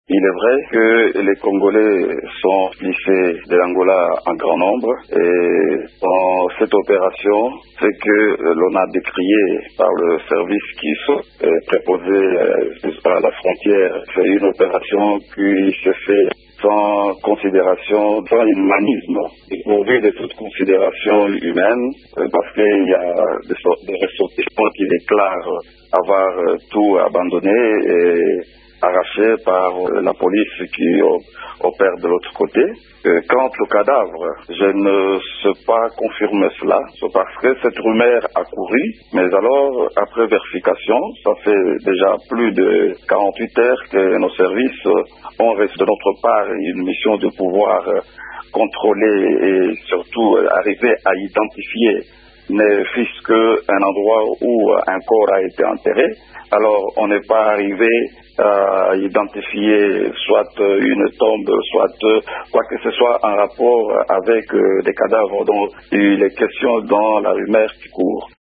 Il l’explique à Radio Okapi:
Vice-gouverneur-expusion.mp3